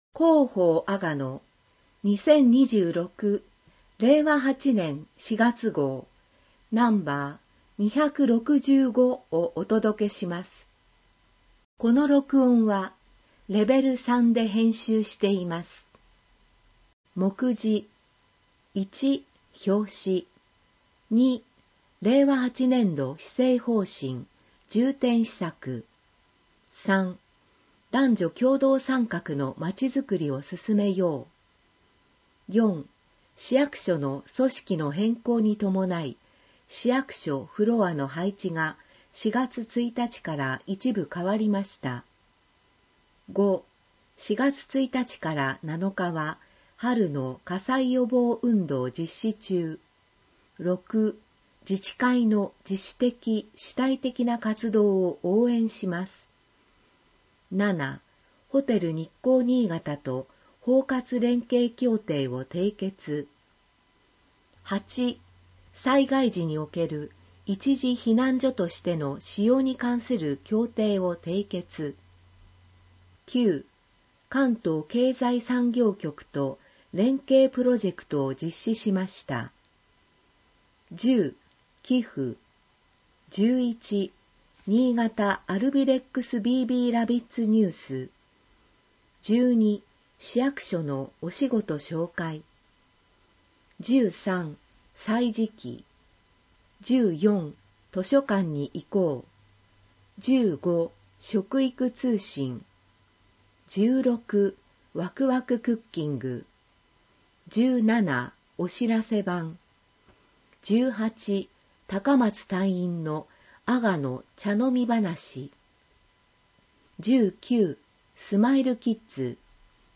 市では、視覚に障がいのある方向けに、ボランティア団体「うぐいす会」の皆さんのご協力により、広報あがのを音声訳したCDを作成し、希望する方に配付しています。